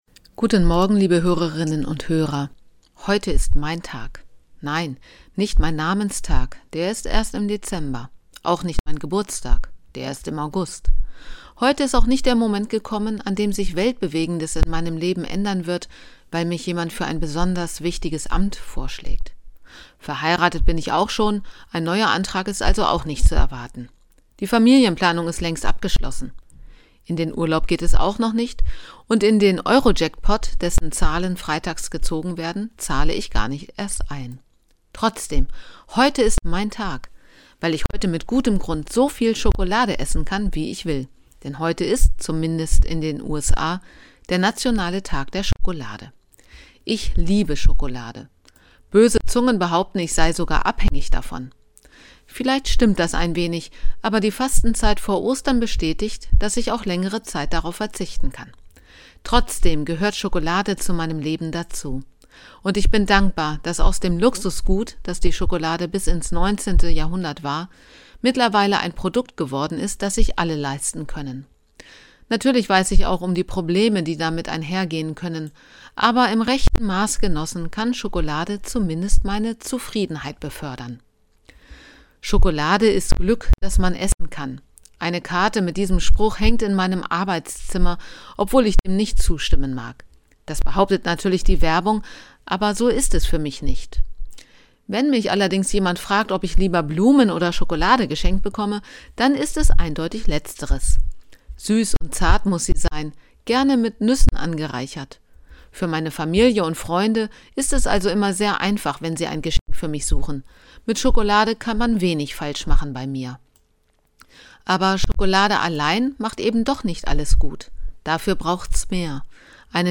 Radioandacht vom 7. Juli